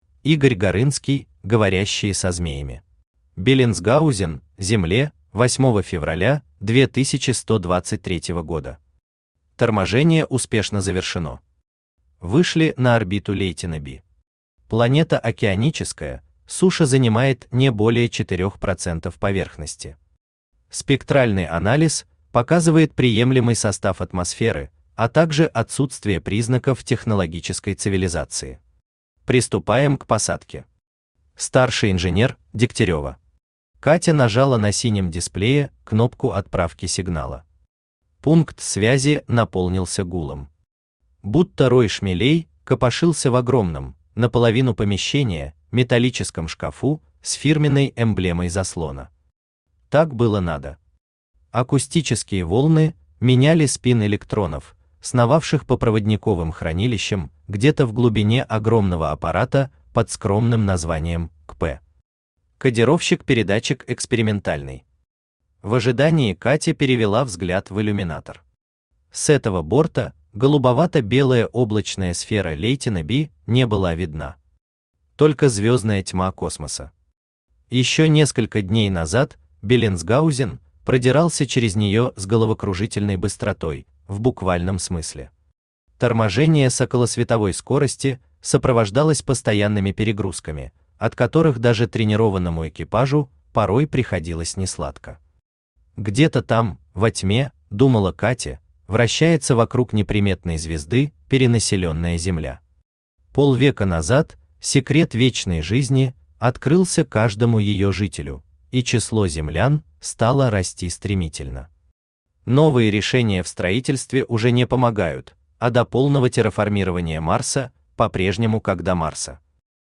Аудиокнига Говорящие со змеями | Библиотека аудиокниг
Aудиокнига Говорящие со змеями Автор Игорь Горынский Читает аудиокнигу Авточтец ЛитРес.